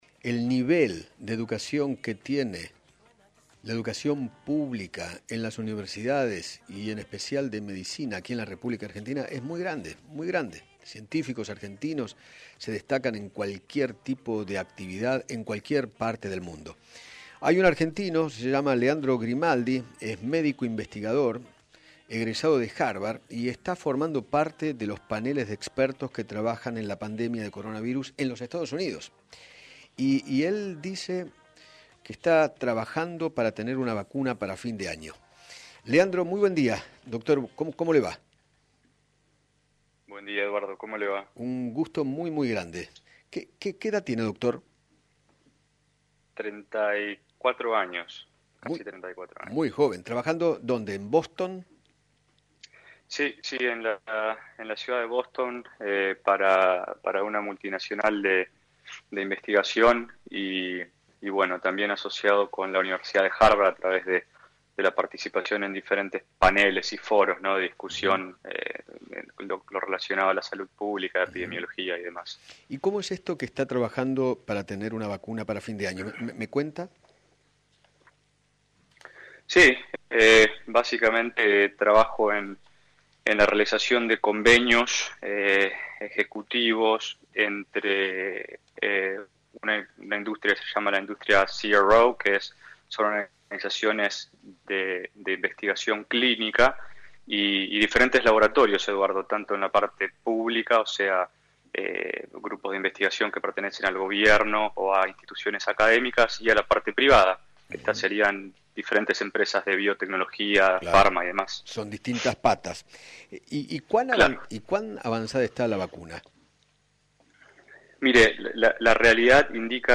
dialogó con Eduardo Feinmann sobre el trabajo que está realizando para encontrar una vacuna contra el Coronavirus en Boston.